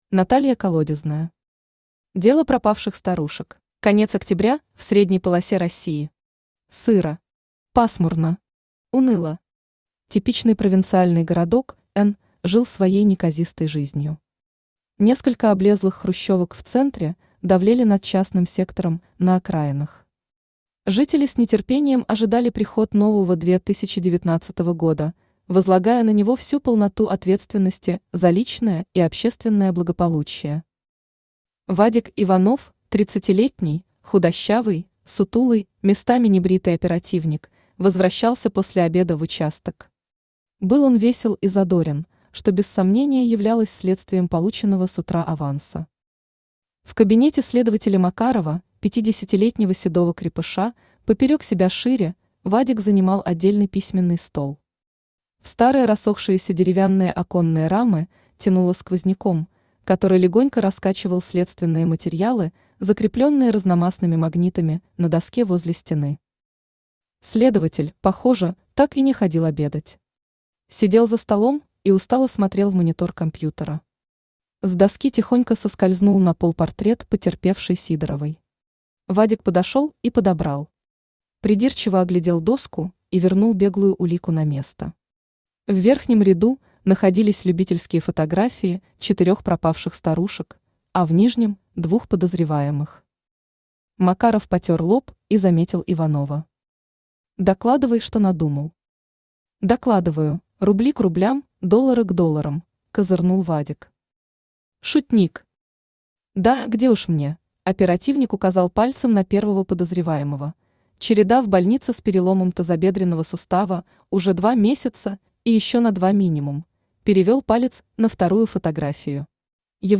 Аудиокнига Дело пропавших старушек | Библиотека аудиокниг
Прослушать и бесплатно скачать фрагмент аудиокниги